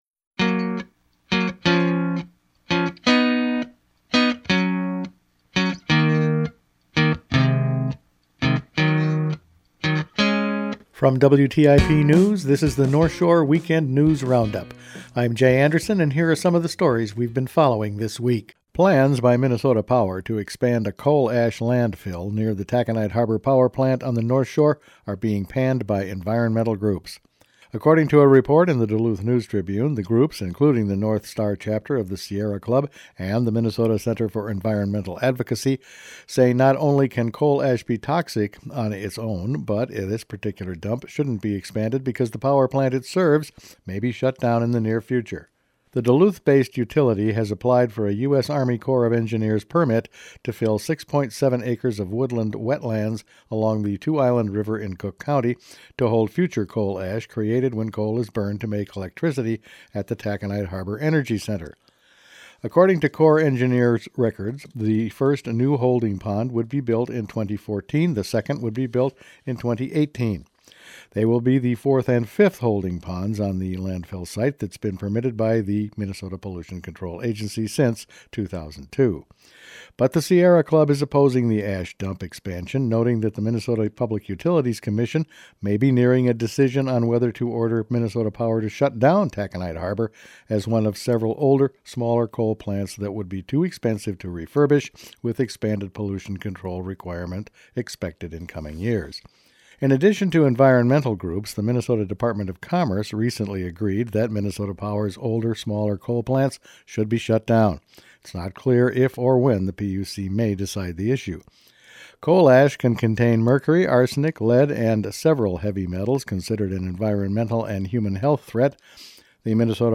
Weekend News Roundup for June 2